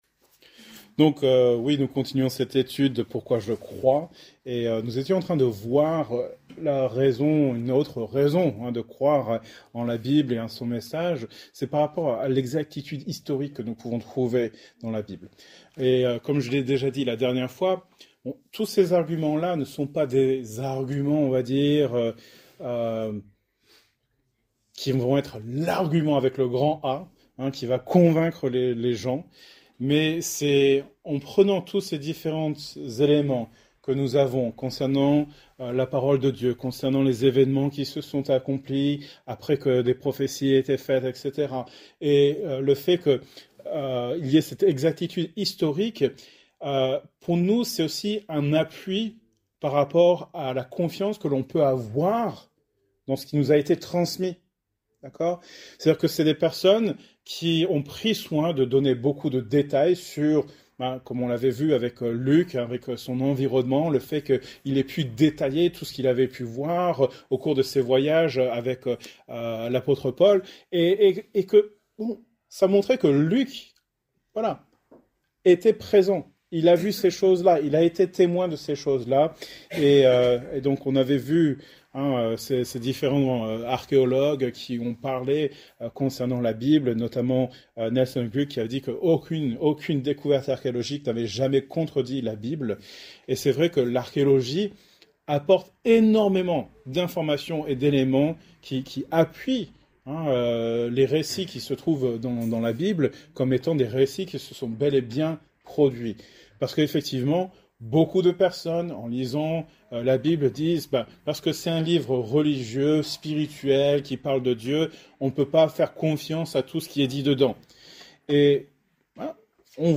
Thème: Apologétique , Foi Genre: Etude Biblique